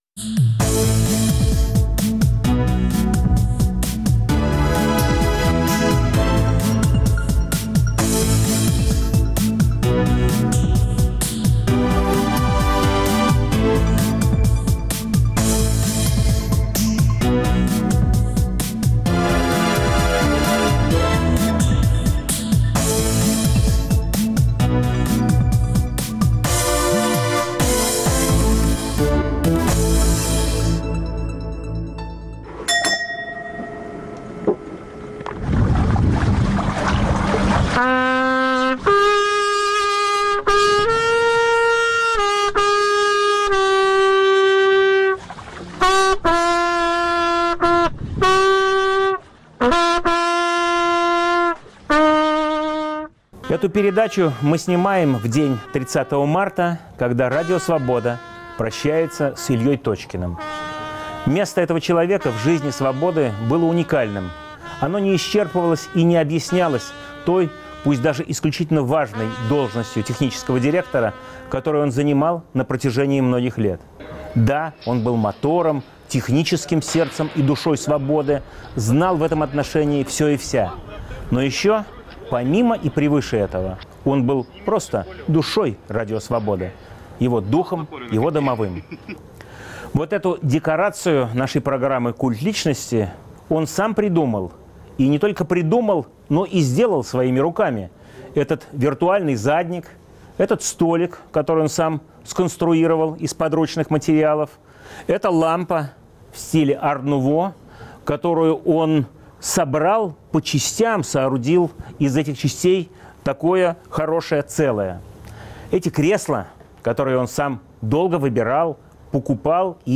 В студии нового выпуска "Культа личности" политик и общественный деятель, руководитель администрации президента Ельцина в 1993-96 гг. Сергей Филатов.